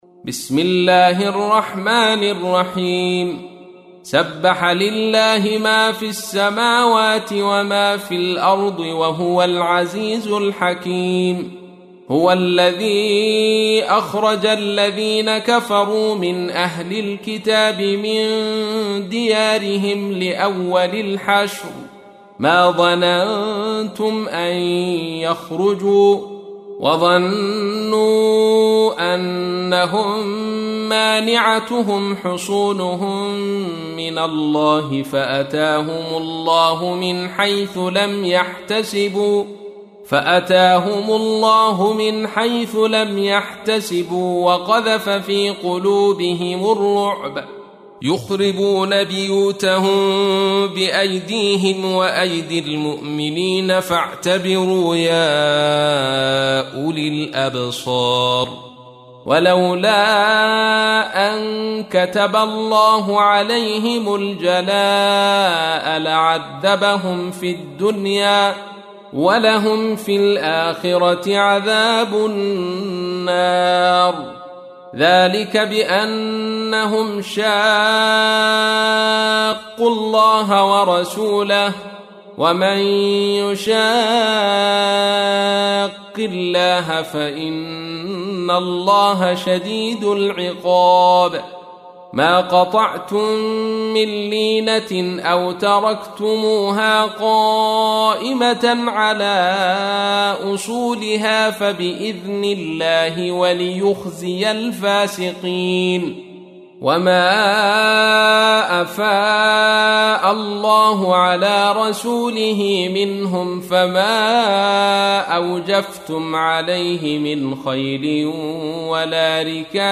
تحميل : 59. سورة الحشر / القارئ عبد الرشيد صوفي / القرآن الكريم / موقع يا حسين